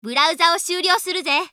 System Voice